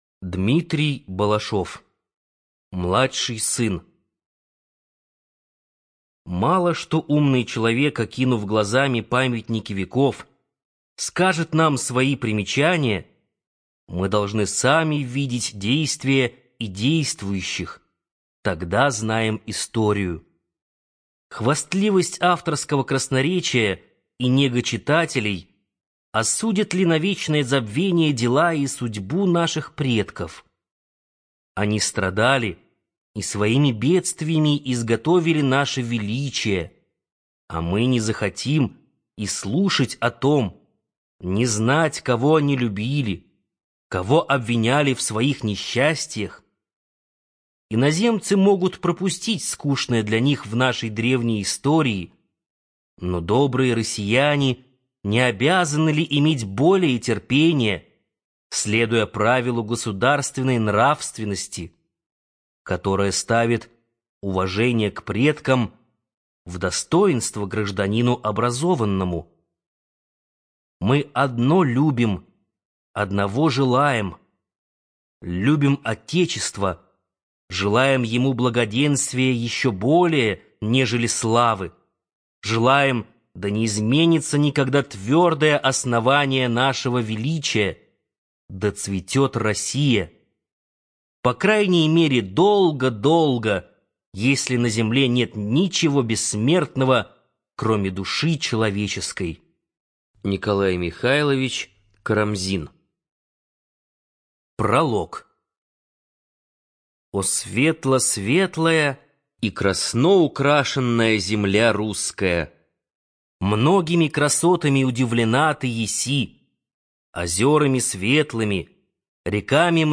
ЖанрИсторическая проза
Студия звукозаписиБиблиофоника